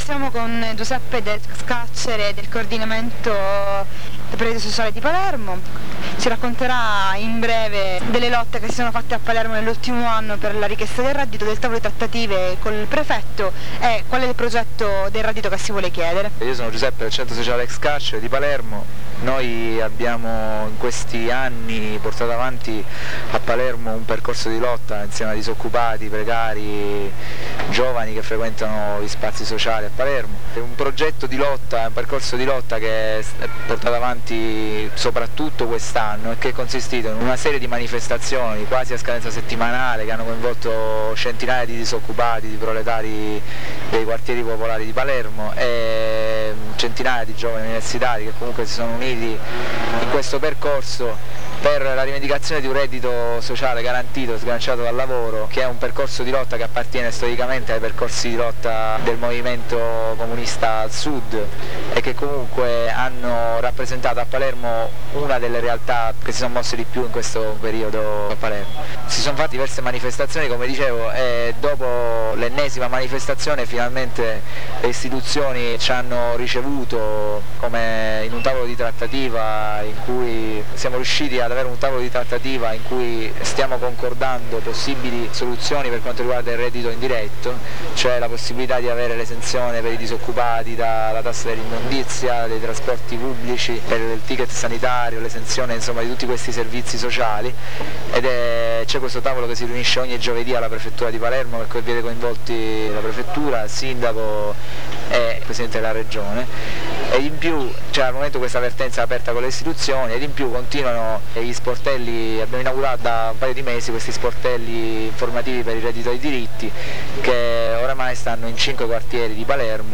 in occazione del forum su mafia, precarieta' e reddito sociale, un membro del coordinamento per il reddito sociale di palermo racconta delle lotte in cui e' impegnato